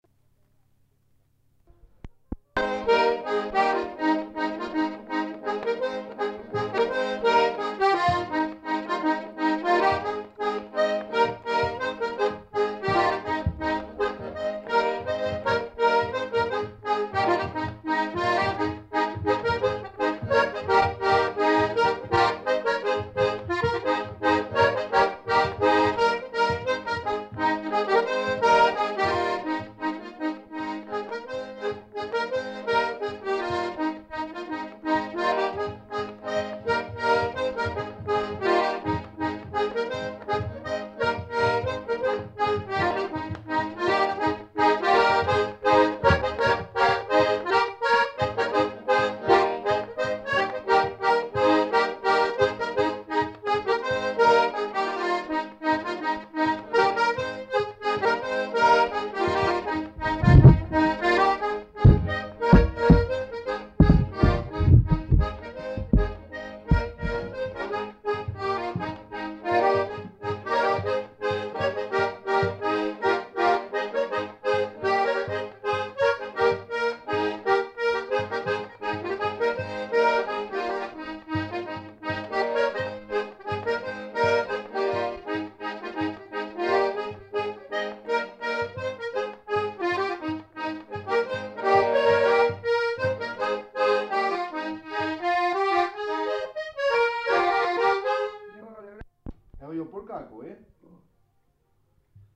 Lieu : Pompiac
Genre : morceau instrumental
Instrument de musique : accordéon diatonique
Danse : mazurka